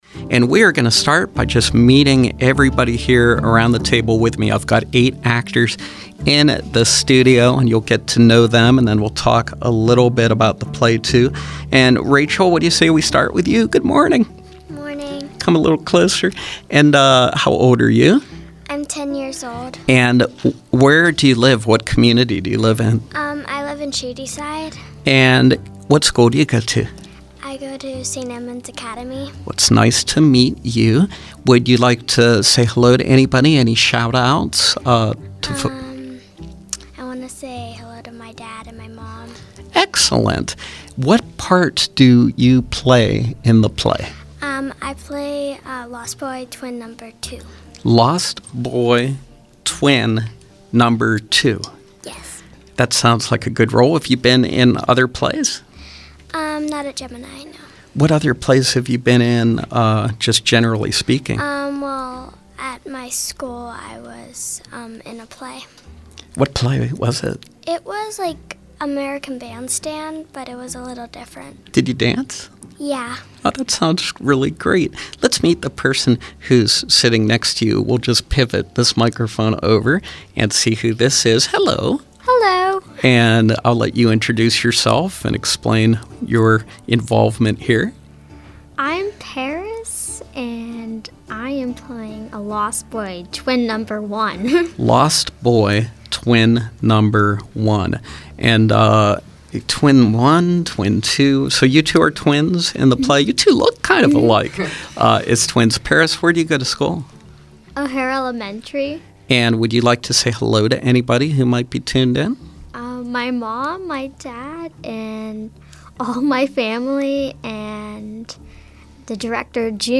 Youth actors
performing selections from the Gemini Theater production of Peter Pan